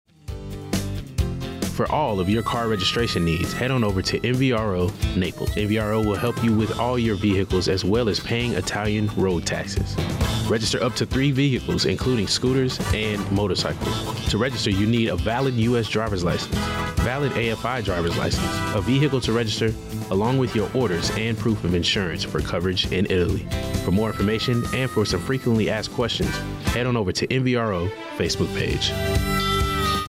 AFN Naples Radio Spot - MVRO Registration